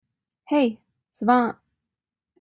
wake-word